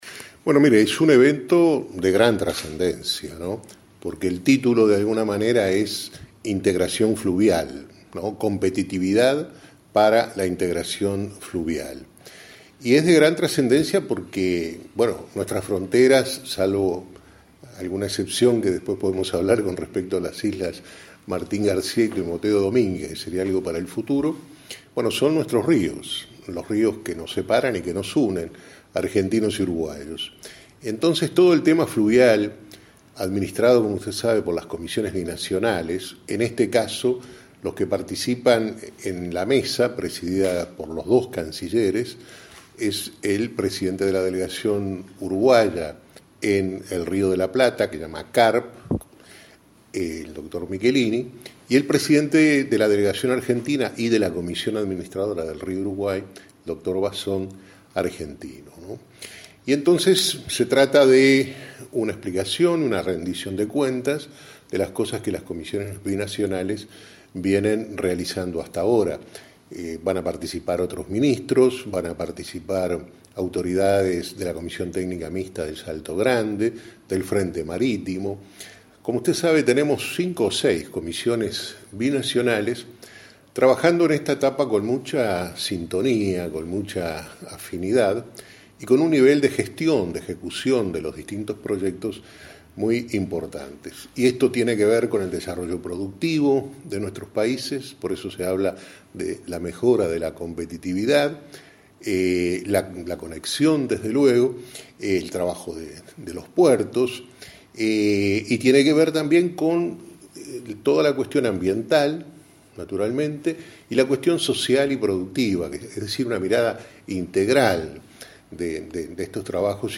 En entrevista con la Secretaría de Comunicación, habló de conectividad y del monitoreo del río Uruguay.